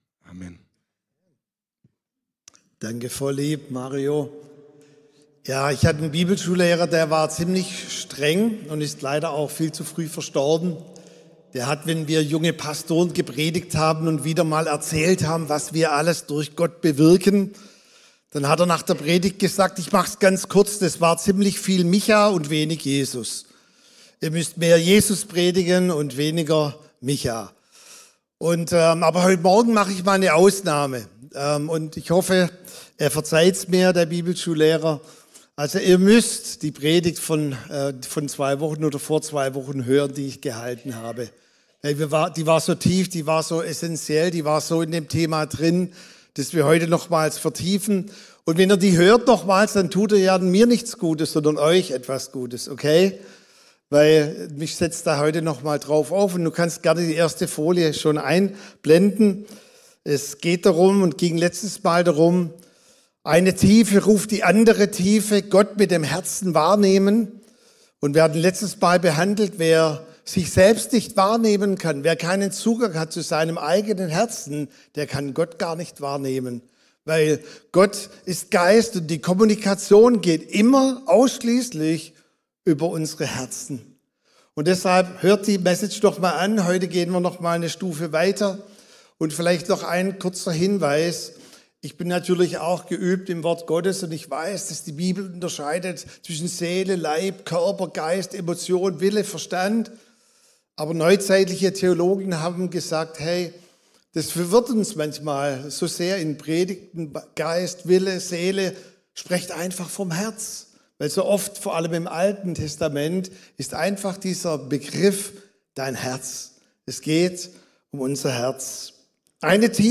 Sonntagspredigten
Wöchentliche Predigten des Christlichen Gemeindezentrums Albershausen